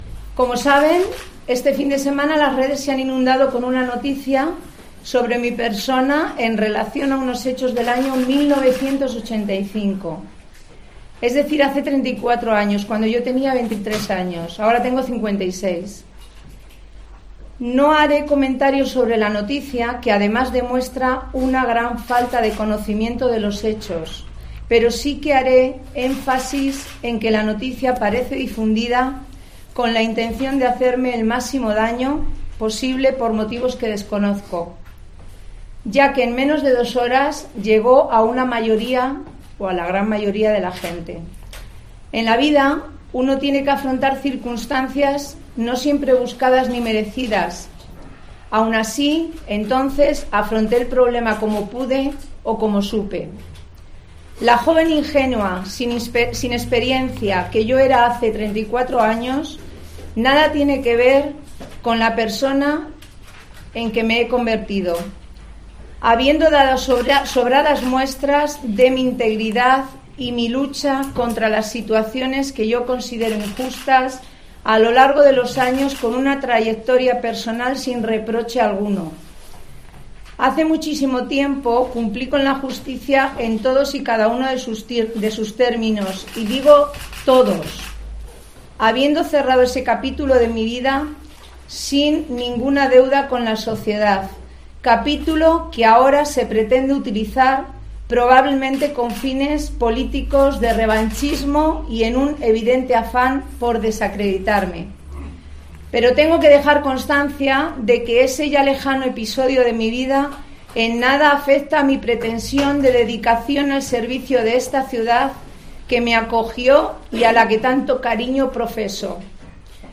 "En la vida uno tiene que afrontar circunstancias no siempre buscadas ni merecidas, aún así entonces afronté el problema como pude o supe", ha explicado leyendo un escrito que ha repartido entre los periodistas con anterioridad, sin entrar en detalles sobre lo sucedido en 1985.